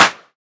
• Hand Clap F# Key 22.wav
Royality free clap sound - kick tuned to the F# note. Loudest frequency: 3562Hz
hand-clap-f-sharp-key-22-ikK.wav